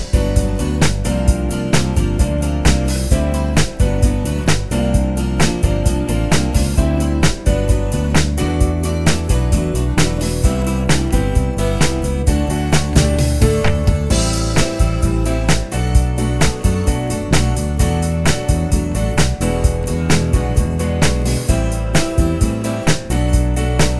no Backing Vocals Soul / Motown 3:54 Buy £1.50